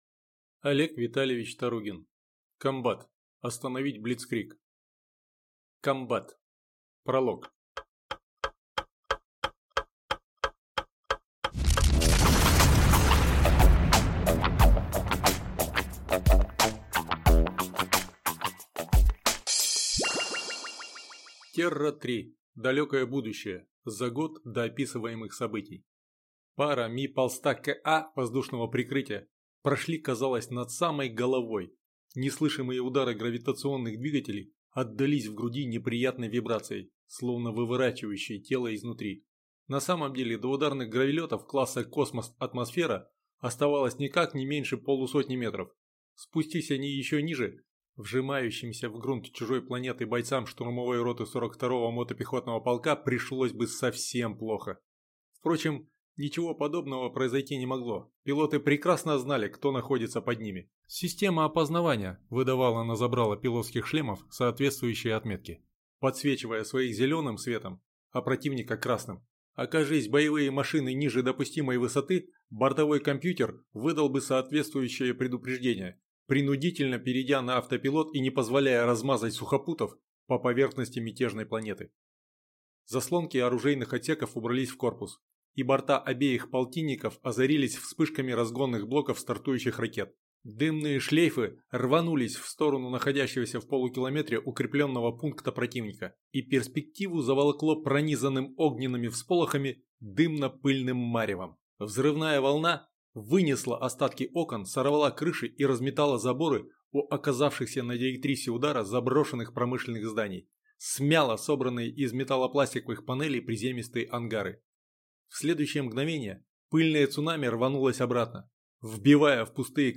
Аудиокнига Комбат. Остановить блицкриг! (сборник) | Библиотека аудиокниг